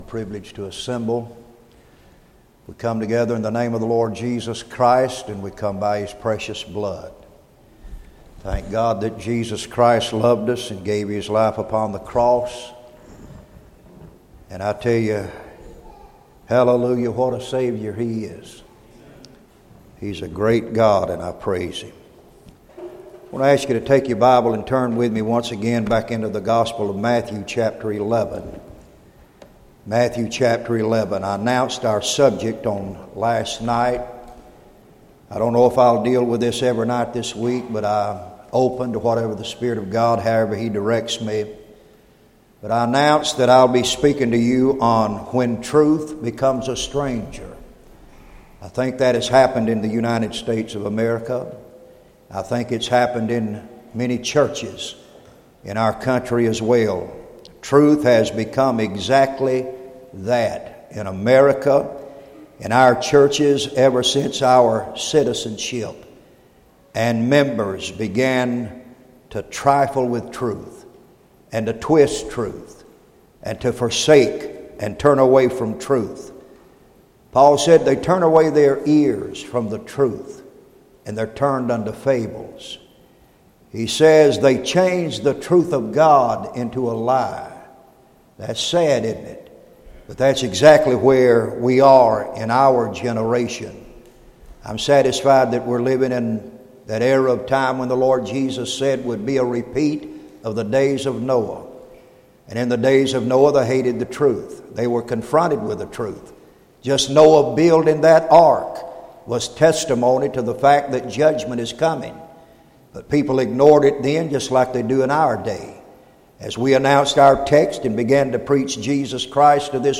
Series: 2014 July Conference
Session: Evening Session